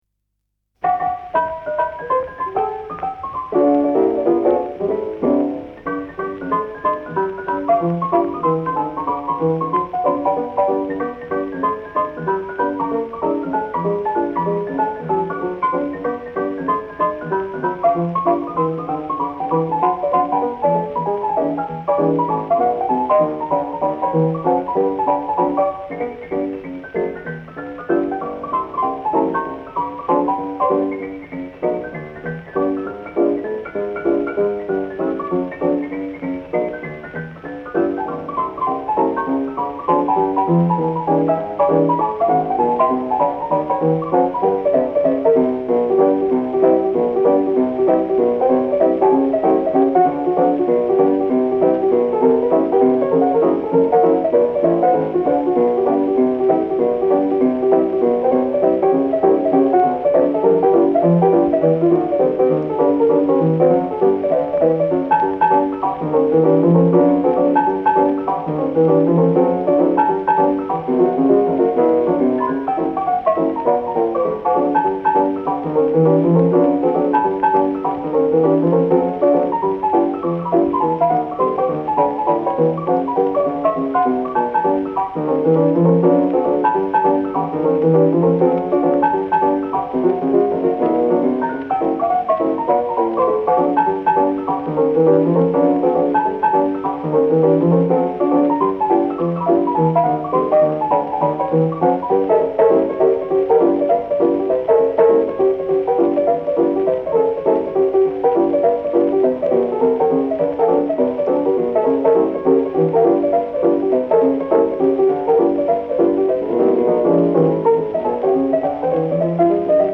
1921 solo